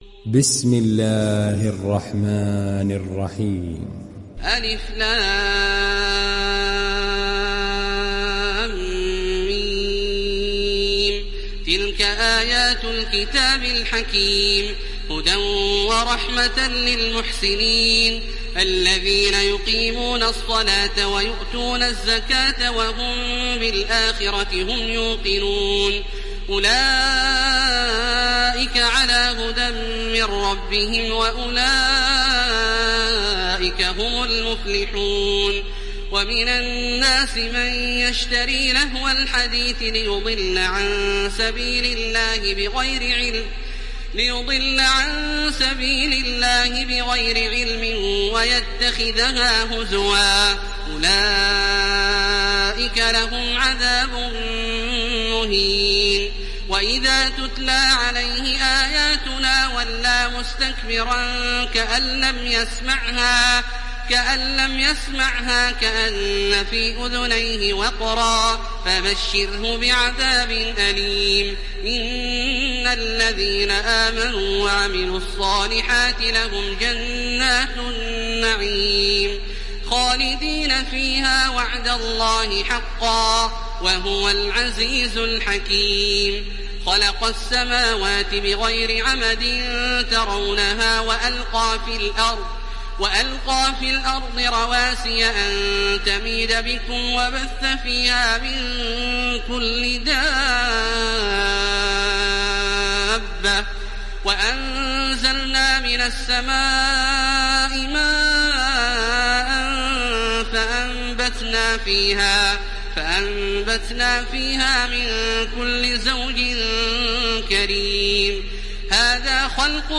Surah Luqman Download mp3 Taraweeh Makkah 1430 Riwayat Hafs from Asim, Download Quran and listen mp3 full direct links
Download Surah Luqman Taraweeh Makkah 1430